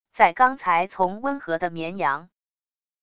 在刚才从温和的绵羊wav音频